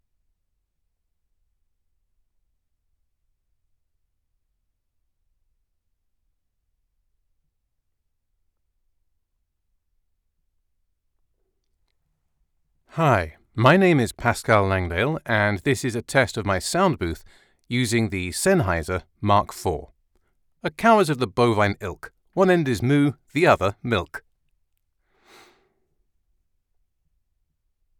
Male
Assured, Versatile, Witty
North London (native), RP (Native), Cockney, Canadian, Montreal English, General American, Southern American, Neutral (Atlantic) English, French (Older generation) French (Americanized) , Neutral (Euro) English.
Microphone: Sennheiser Mk4 | Shure Sm7b